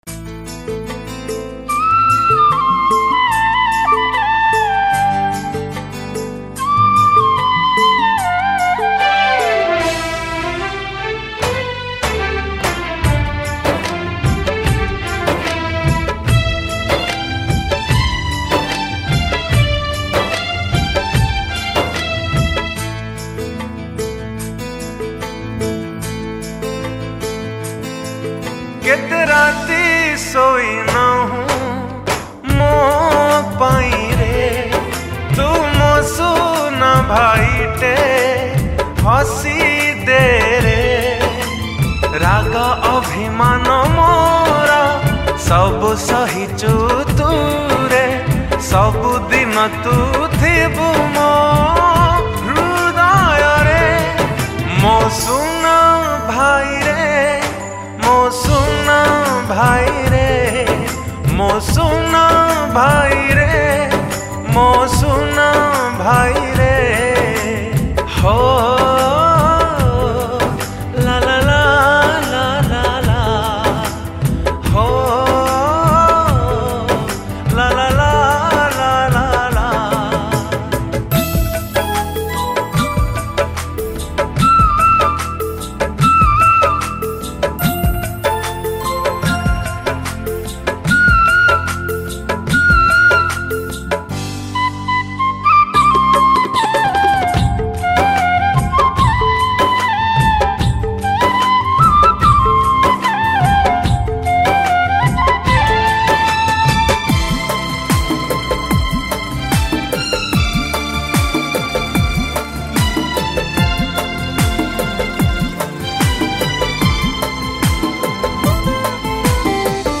(STUDIO VERSION ) *SINGER